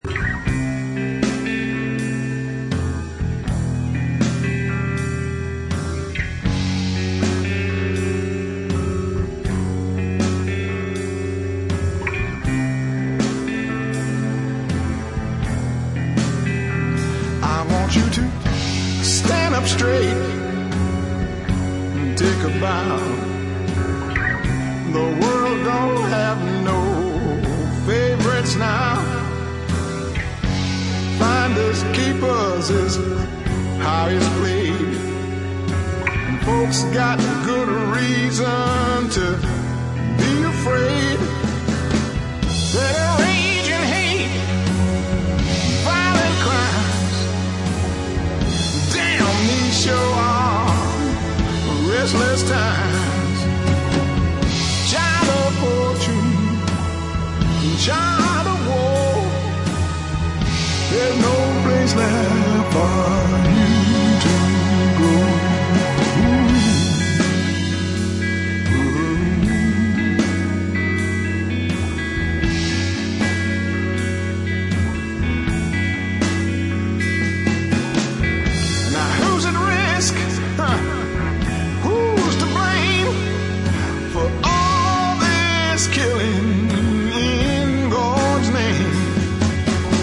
Blues, Funk / soul